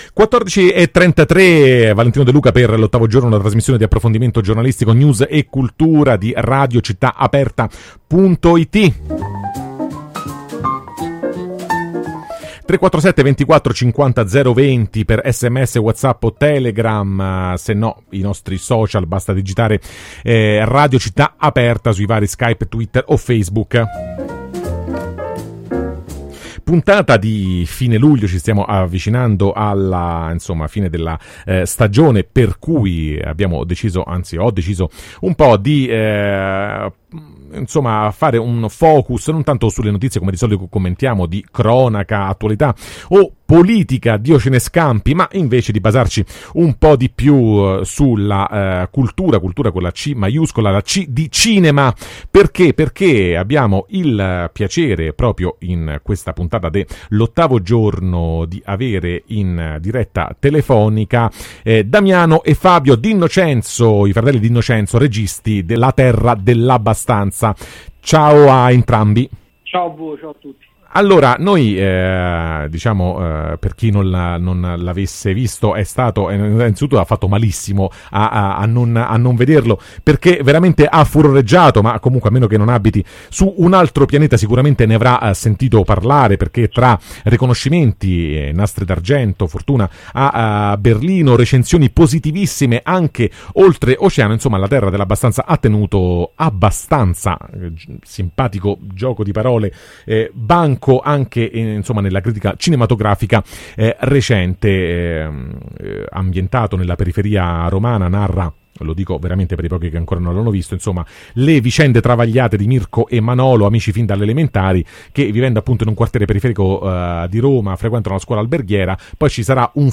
“La terra dell’abbastanza” intervista ai registi D’Innocenzo | Radio Città Aperta